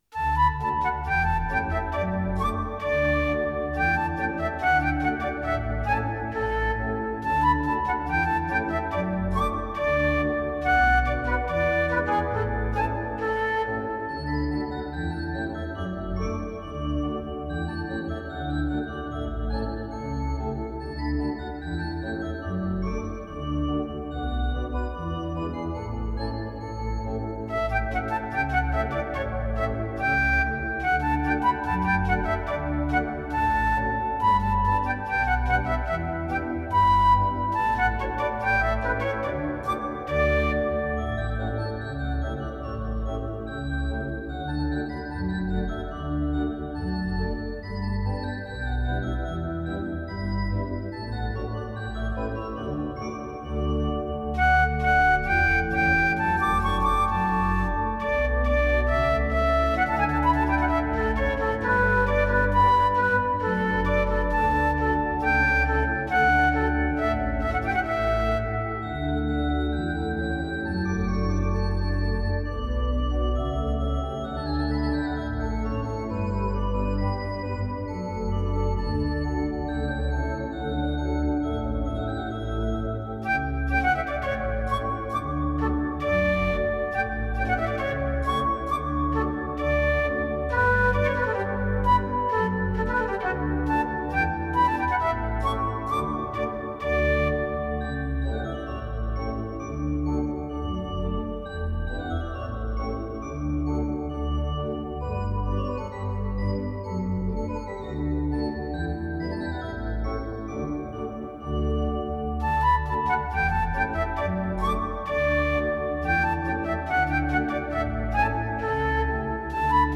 We starten het nieuwe jaar met een vrolijke Gavotte.
Zelf heb ik ervoor gekozen om één van de solo-instrumenten in te zetten, namelijk de dwarsfluit. Daardoor danst en huppelt het stuk als het ware het nieuwe jaar in.
Met die elegantie hebben we een bijzonder charmant en aantrekkelijk stuk in handen.